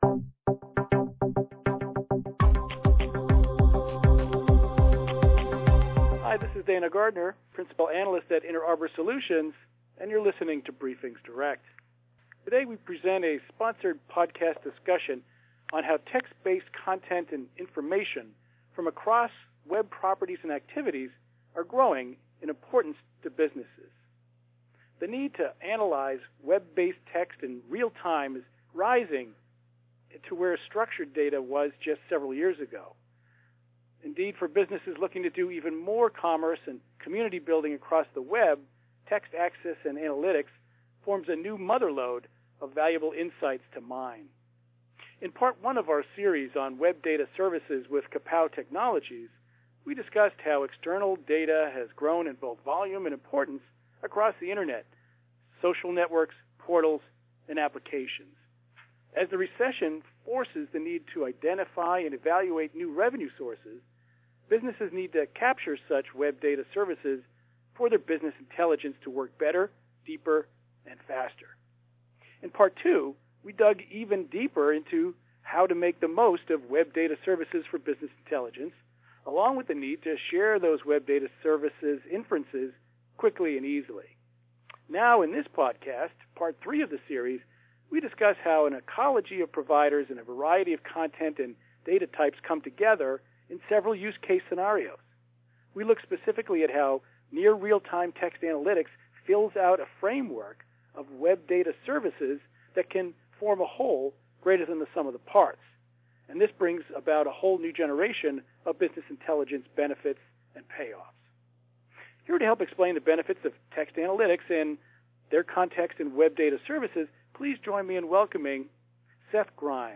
Transcript of a sponsored BriefingsDirect podcast on information management for business intelligence, one of a series on web data services with Kapow Technologies.